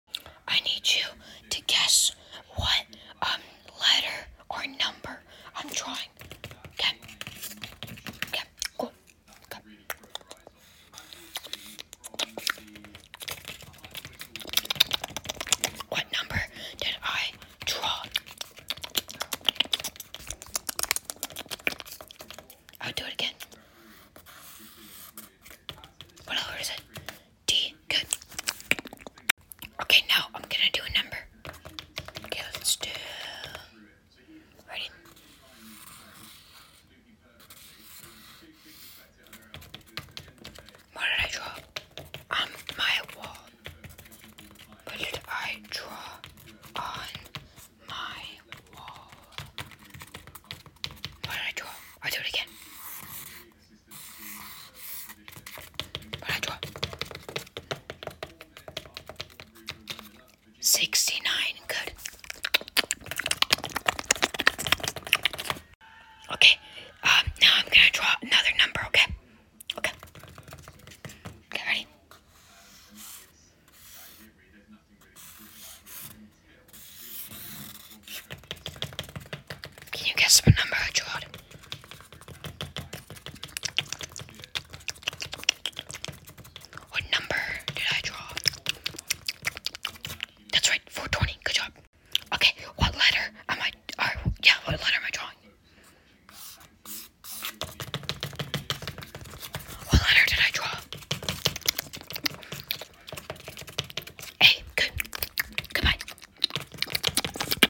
ASMR GUESSE THE NUMBER OR sound effects free download